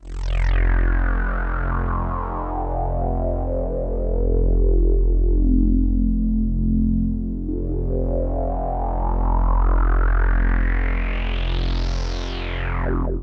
synth04.wav